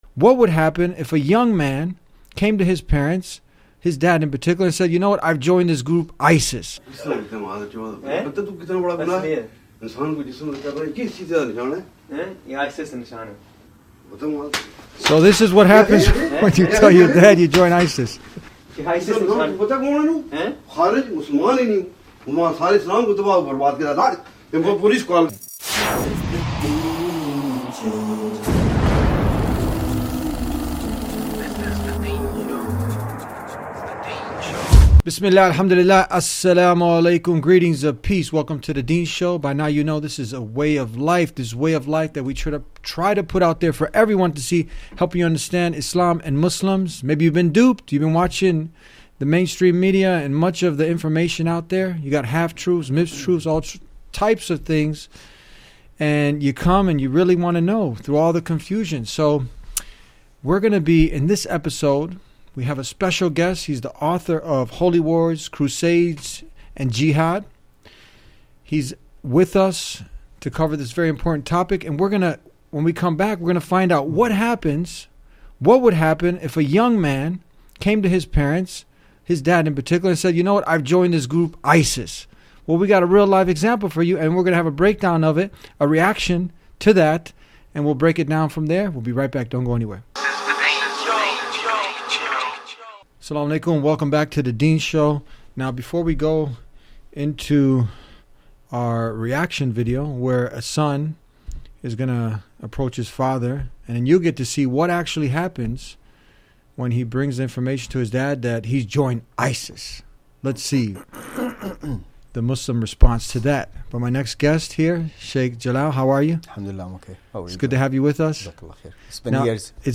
Through the interaction between the host, the guest, and the video of the prank, the episode effectively conveys the message that mainstream Muslims like the father in the scenario reject extremist ideologies and adhere to the peaceful principles of Islam.